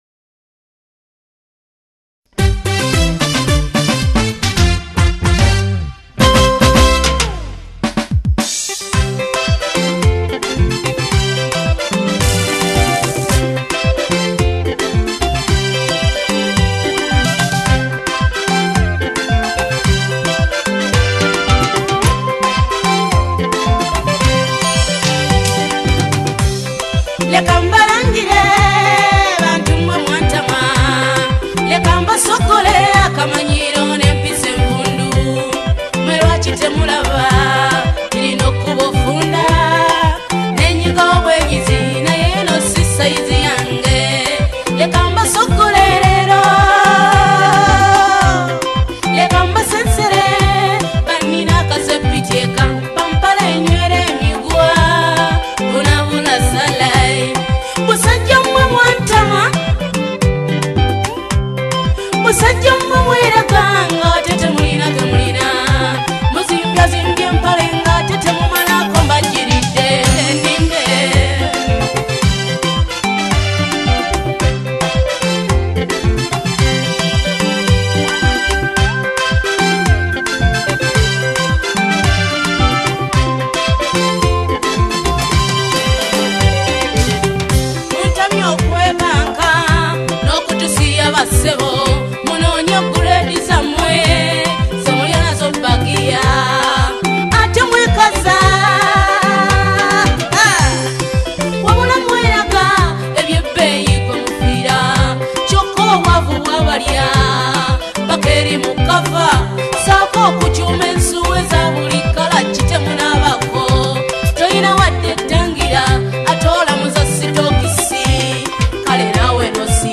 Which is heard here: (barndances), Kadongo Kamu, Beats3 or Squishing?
Kadongo Kamu